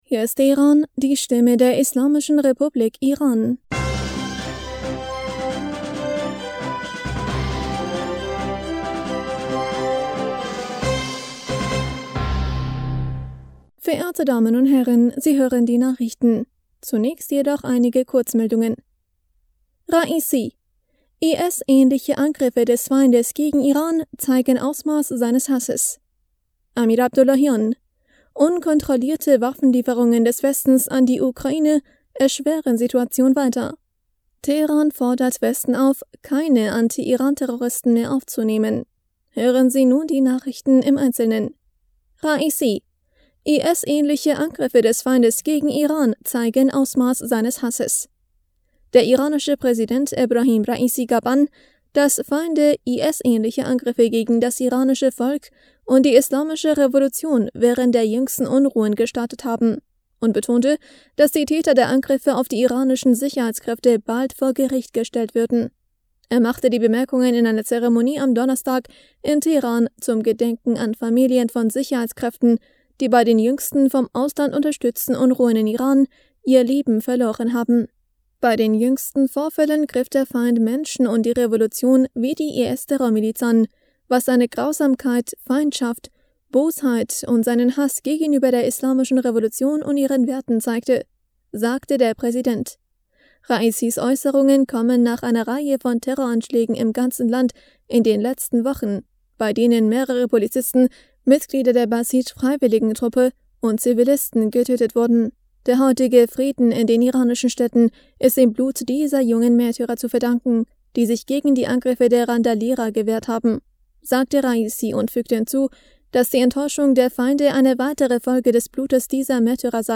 Nachrichten vom 9. Dezember 2022